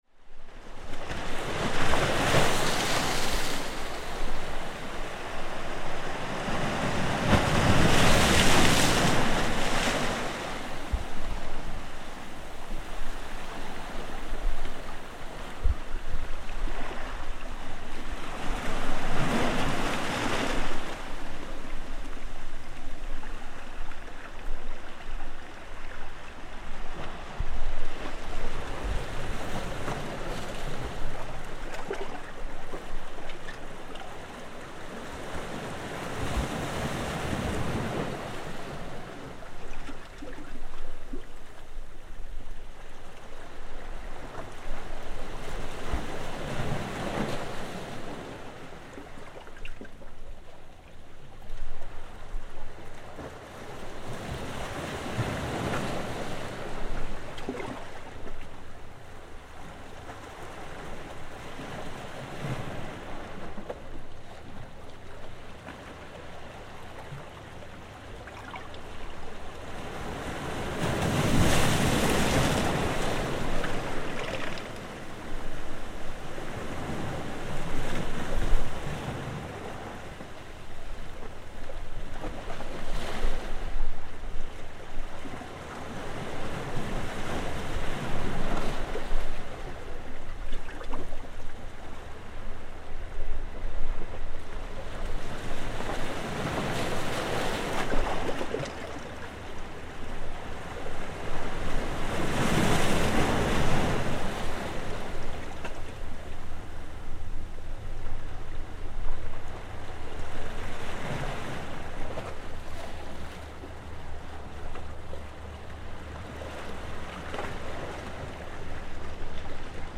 Crashing waves on Elgol beach
Waves crash onto a rocky beach in Elgol, with the close sound of water smacking against the hard rocks of the beach. One boat passes by into the harbour during the recording.
Recorded on the Isle of Skye by Cities and Memory.